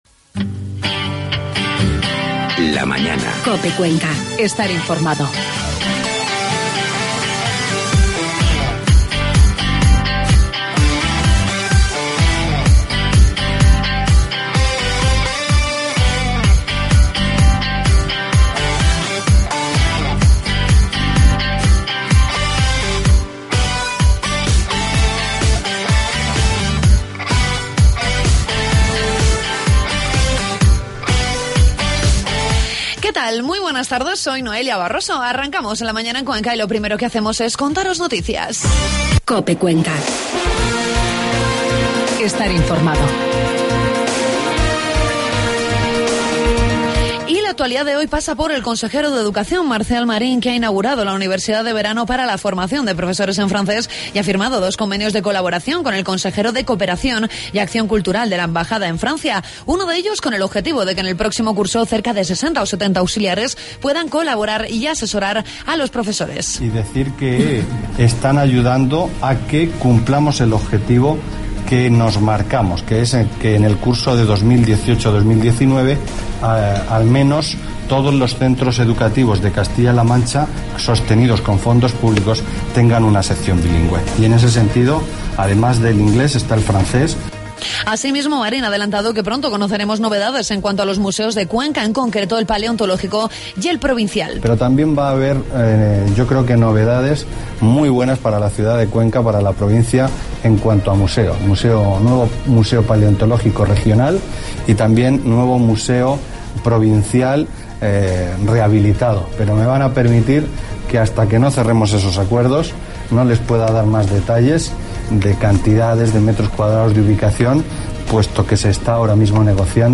Entrevistamos al concejal de Cultura del Ayuntamiento de Huete que nos cuenta el encuentro de "Huetes" que se va a llevar a cabo en la localidad el próximo 19 de julio.